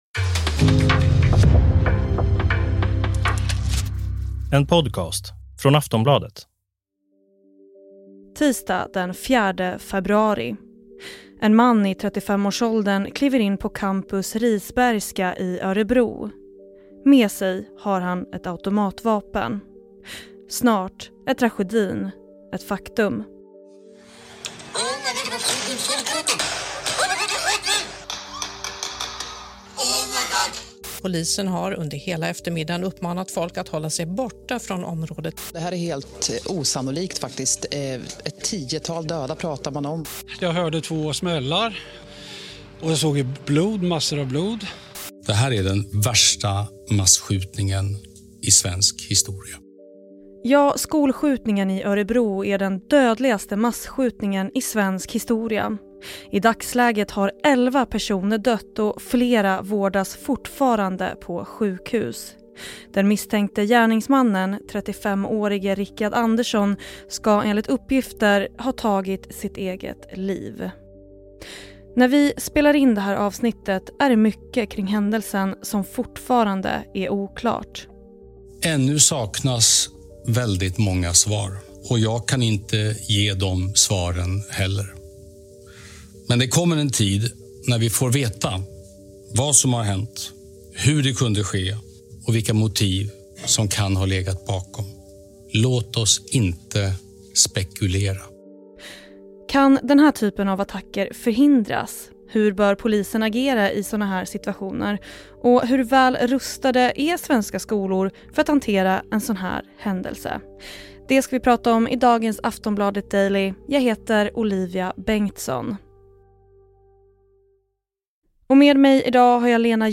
Klipp i avsnittet: Aftonbladet, Sveriges Radio Ekot, P4 Örebro.